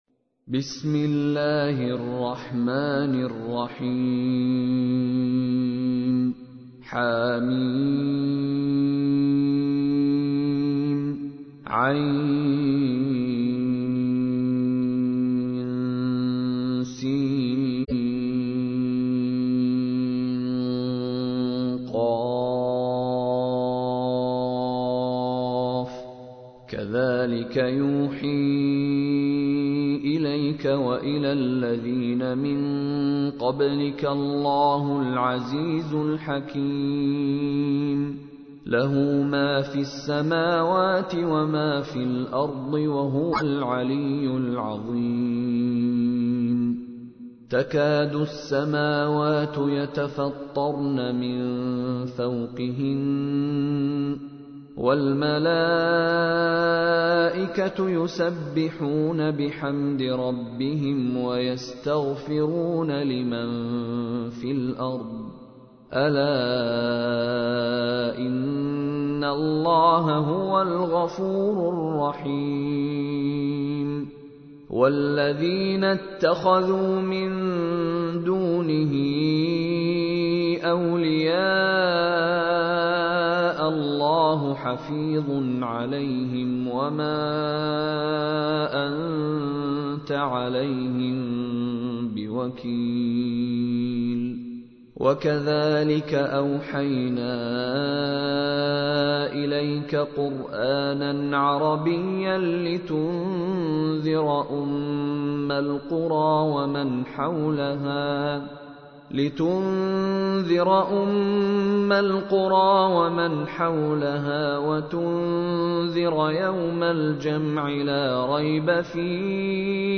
تحميل : 42. سورة الشورى / القارئ مشاري راشد العفاسي / القرآن الكريم / موقع يا حسين